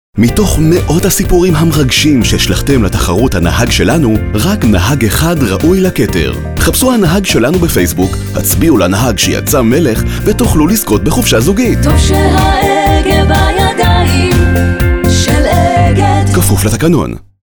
Native speaker Male 30-50 lat
Demo lektorskie
Spot reklamowy